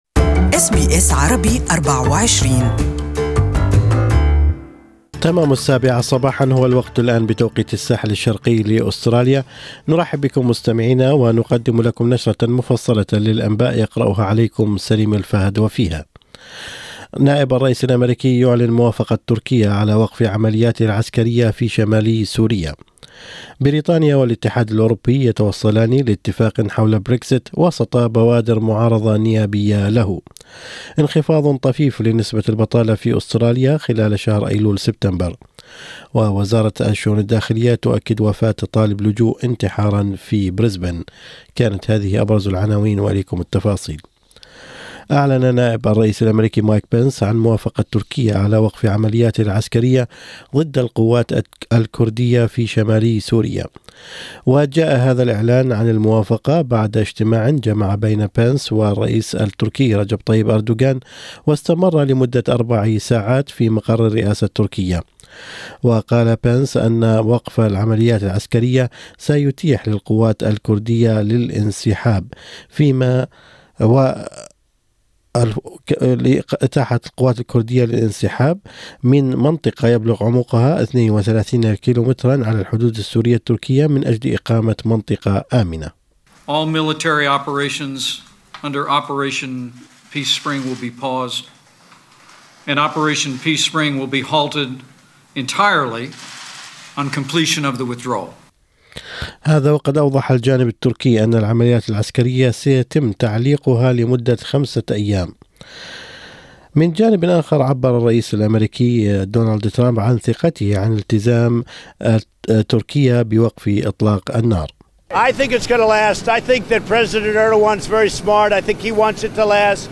أخبار الصباح: الداخلية تؤكد انتحار لاجئ في فندق ببريزبن